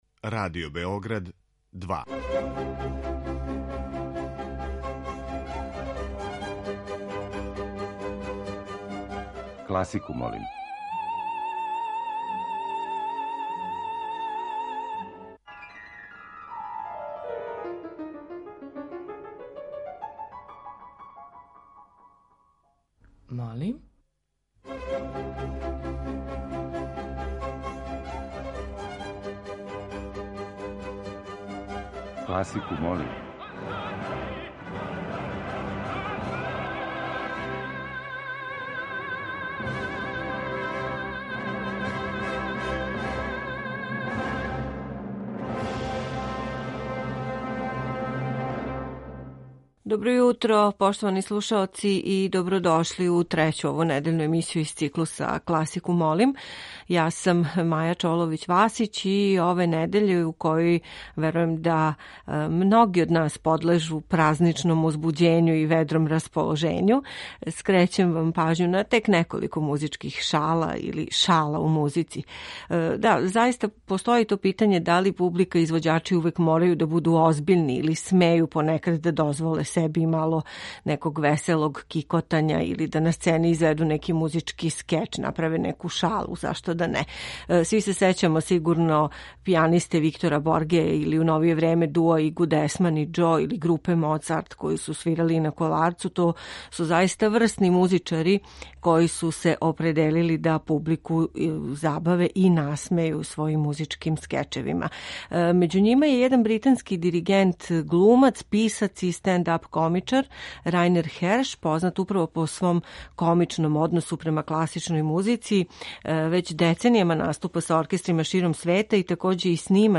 Међу њима је и неколико духовитих и шаљивих примера композиторске и извођачке креације, као део овонедељне теме, а слушаћете и четири снимка са концерата одржаних у оквиру 55. фестивала „Мокрањчеви дани".